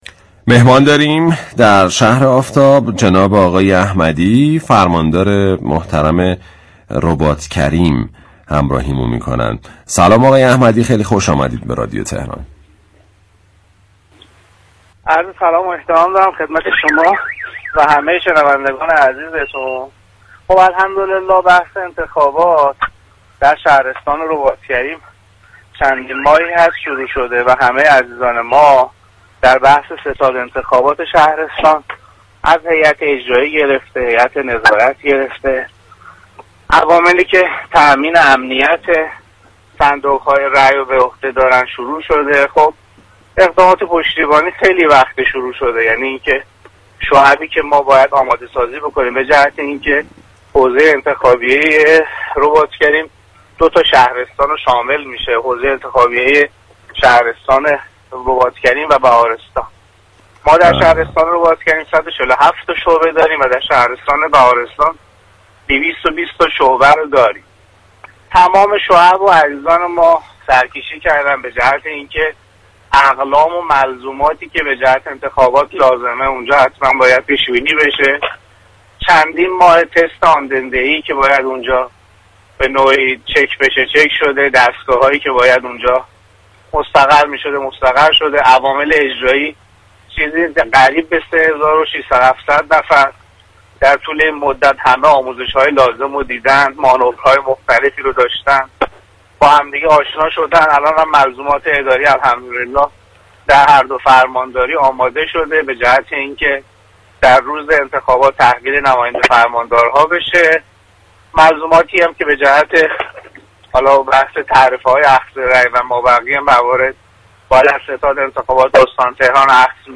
به گزارش پایگاه اطلاع رسانی رادیو تهران، امیداحمدی فرماندار رباط كریم در گفت و گو با «شهر آفتاب» اظهار داشت: حوزه انتخابیه رباط كریم شامل دو شهرستان رباط كریم و بهارستان است.